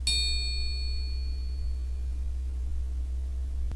Christmas Sound Effects
016 ping #2.wav